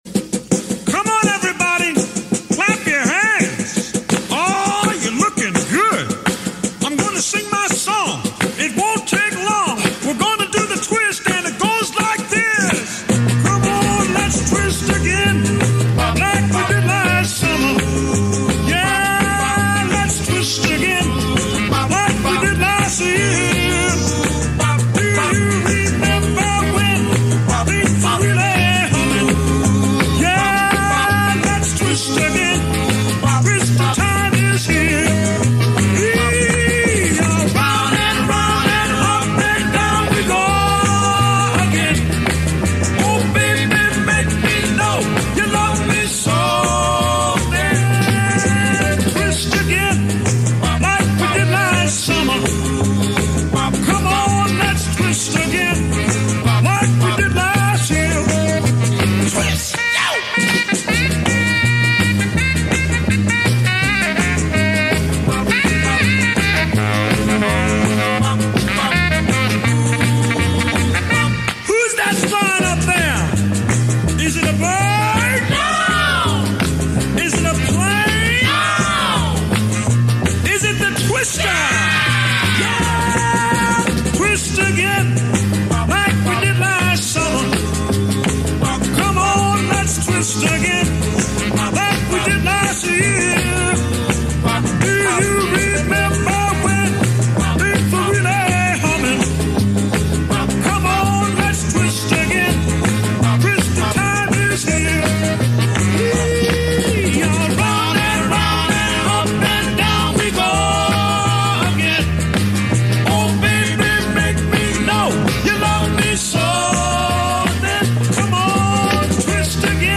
Heute mit dem Trabant mal das Treffen in Mieste besucht. Ostfahrzeuge in der Überzahl